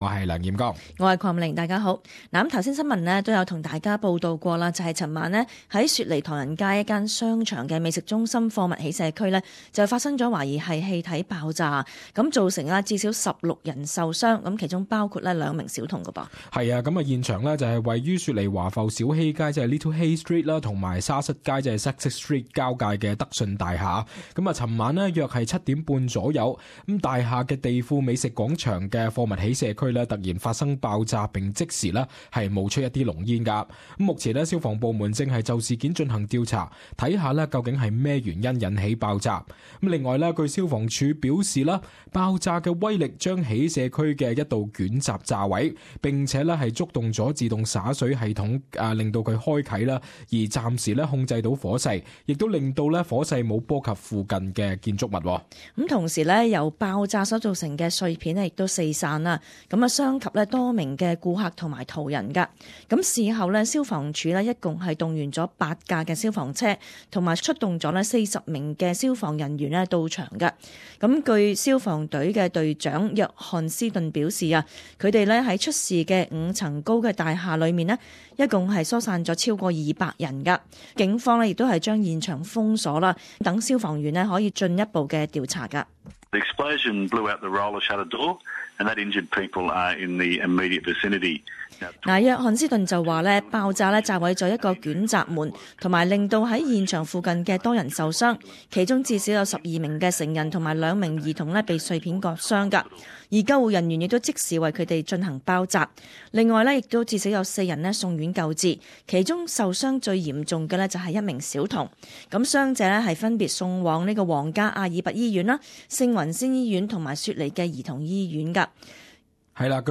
【時事報導】雪梨唐人街商廈發生爆炸多人受傷